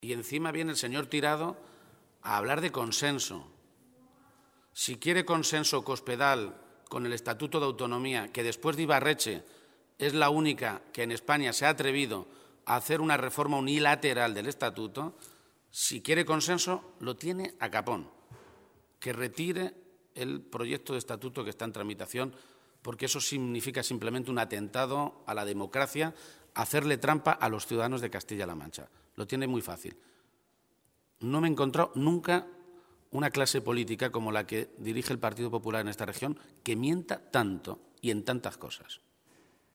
Se pronunciaba de esta manera García-Page esta mañana, en Toledo, a preguntas de los medios de comunicación, después de que la Cadena Ser informara esta mañana que la Justicia investiga e identifica la posible existencia de supuestas cajas B para la financiación del PP en cinco comunidades autónomas, entre las que cita a Castilla-La Mancha.
Cortes de audio de la rueda de prensa